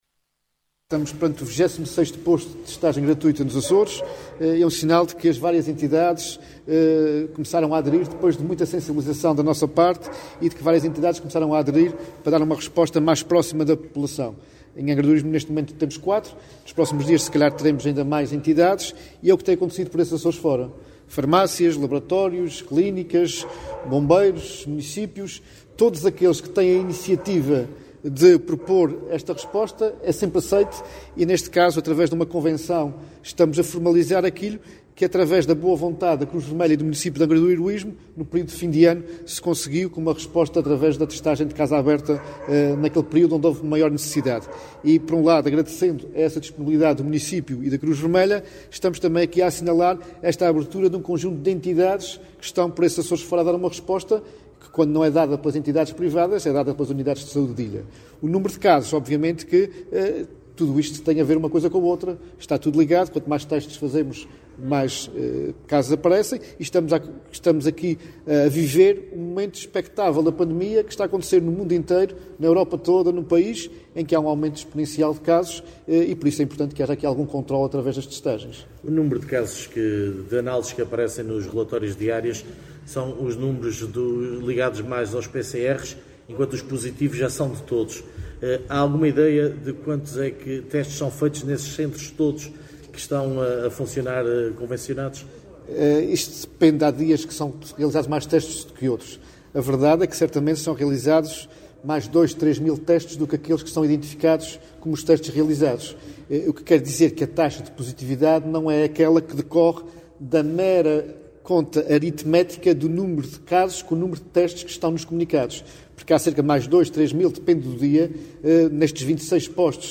O Secretário Regional da Saúde e Desporto, Clélio Meneses, marcou presença, na tarde de quinta-feira, na abertura do posto de testagem à covid-19 da Delegação da Cruz Vermelha de Angra do Heroísmo.